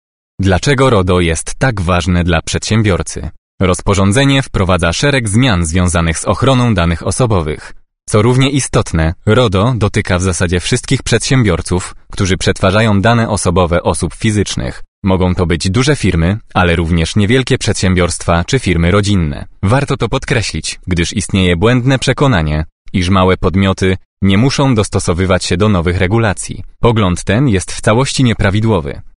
Owner of a pleasant, colorful and energetic voice that works well for announcements, commercials, narration and audiobooks.
E-learning
06.-E-learning-RODO.mp3